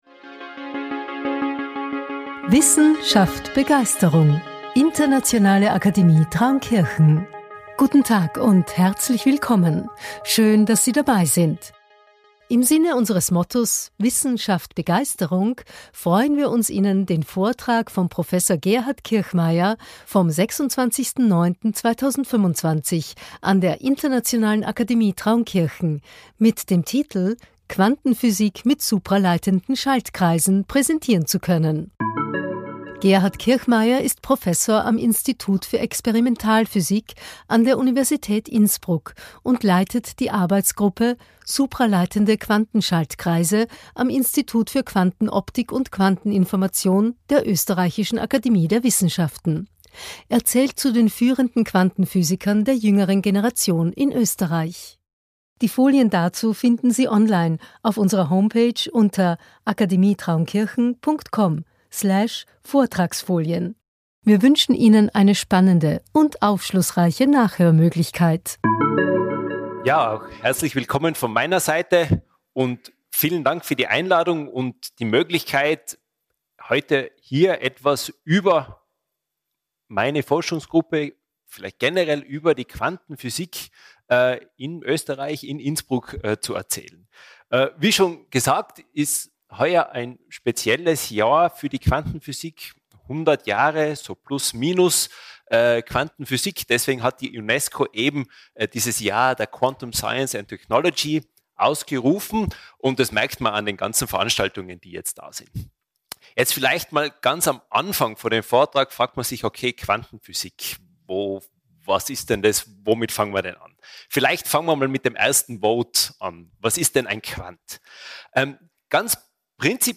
IAT Science-Cast Folge 05 Quantenphysik mit supraleitenden Schaltkreisen ~ IAT ScienceCast - Einblicke in Naturwissenschaft und Technik aus dem Klostersaal Traunkirchen Podcast